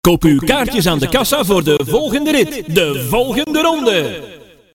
Kermis geluid Koop uw kaartjes aan de kassa
Categorie: Geluidseffecten
Geniet van authentieke kermisgeluiden, van de verkopers tot de drukte van de attracties.
kermis geluiden, geluidseffecten
kermis-geluid-koop-uw-kaartjes-aan-de-kassa-nl-www_tiengdong_com.mp3